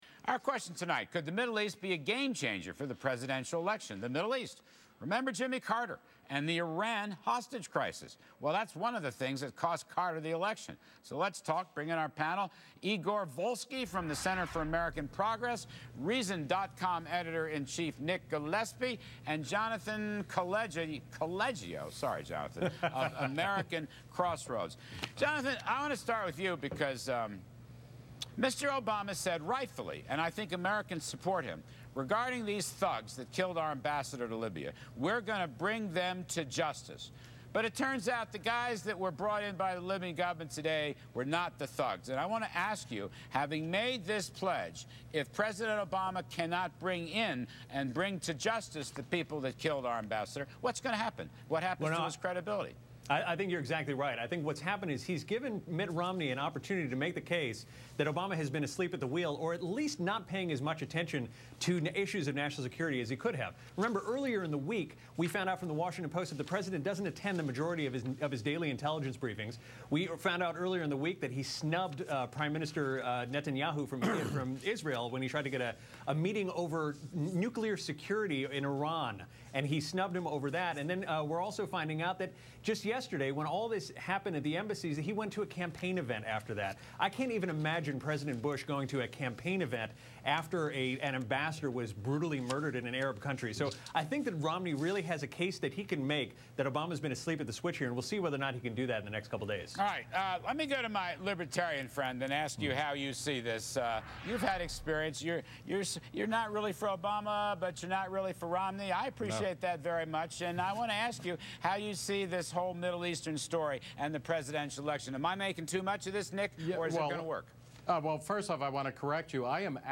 Reason TV's Nick Gillespie appears on The Kudlow Report saying President Obama has "no guiding principle in his foreign policy" but still expects the president to come out ahead of challenger Romney who offers little to no real alternative.